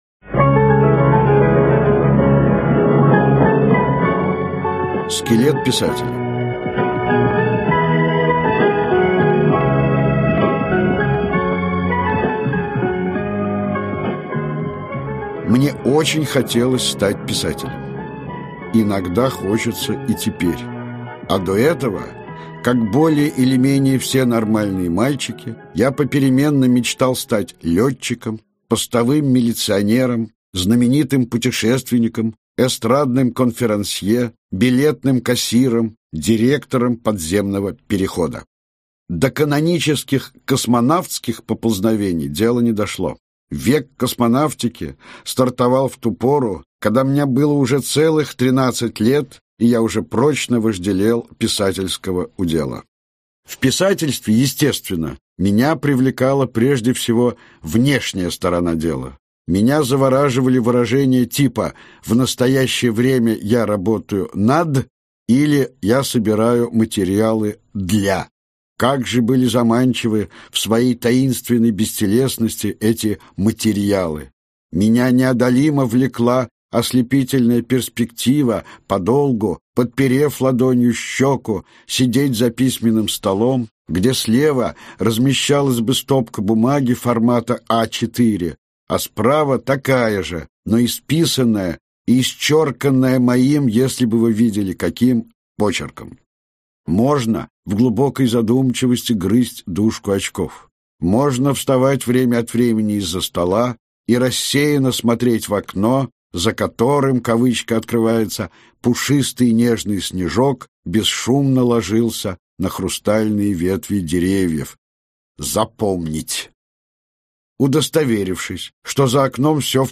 Аудиокнига Духи времени | Библиотека аудиокниг
Aудиокнига Духи времени Автор Лев Рубинштейн Читает аудиокнигу Лев Рубинштейн.